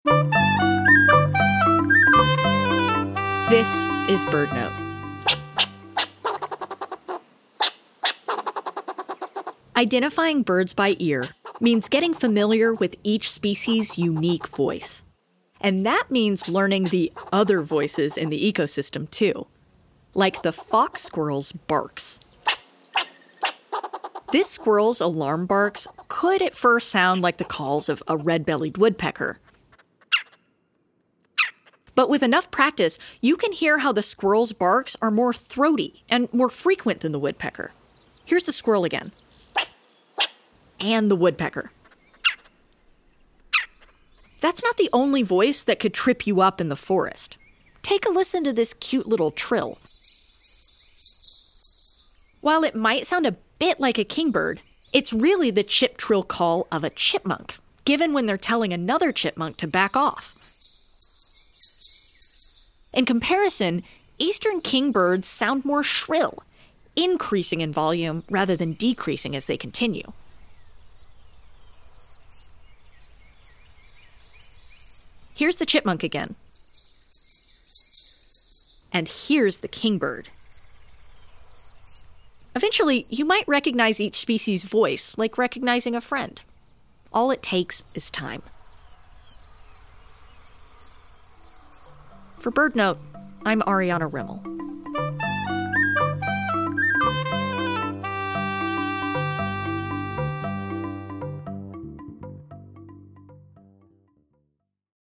Squirrels and chipmunks make calls that can sound a bit like bird calls at times.